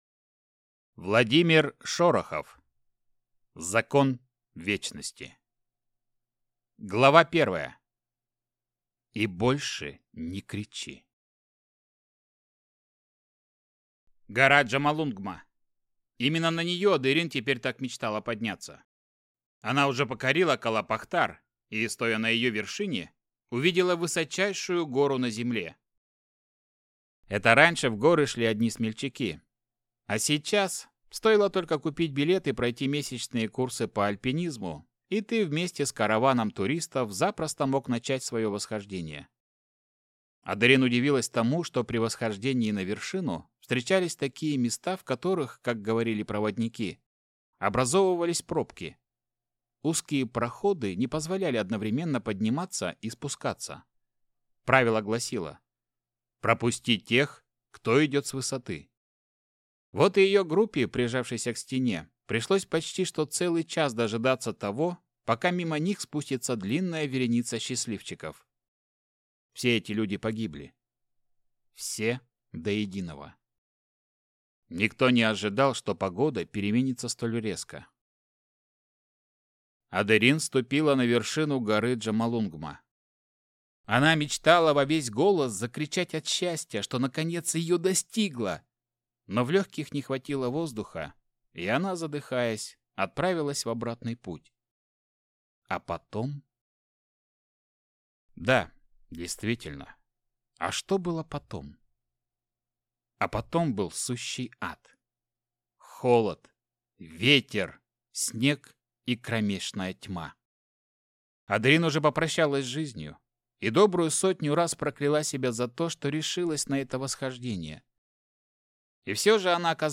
Aудиокнига Закон вечности